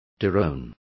Complete with pronunciation of the translation of dethroned.